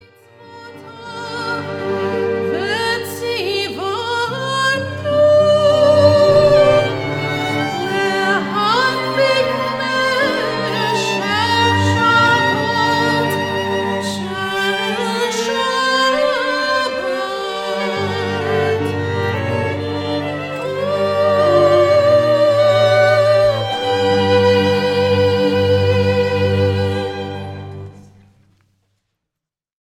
A selection of beautifully arranged music for Shabbat